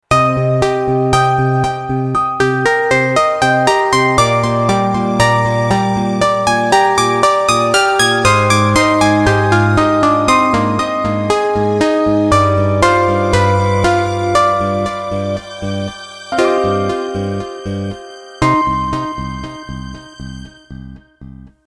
رینگتون نرم و بیکلام